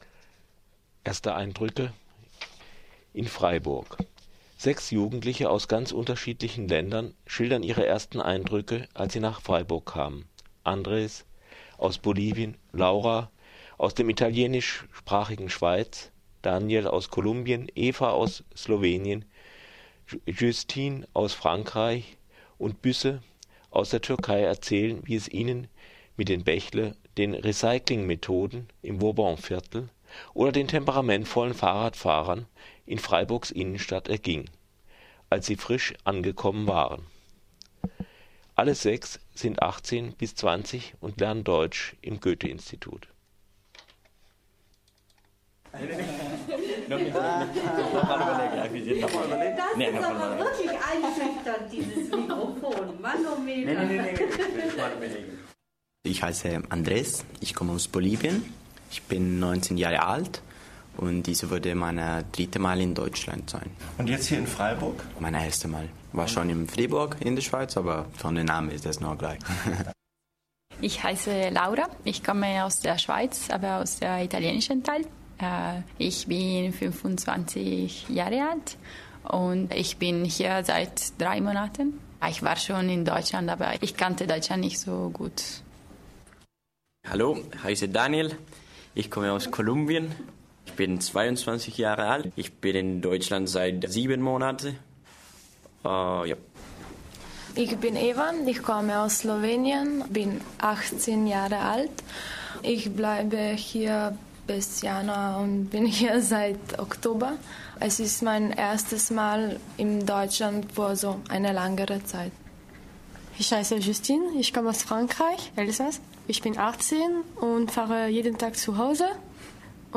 Sechs Jugendliche aus verschiedenen Ländern schildern ihre ersten Eindrücke in Freiburg: Bächle, Umweltschutz, Leben auf den Straßen und brüllende Fahrradfahrer im Rücken.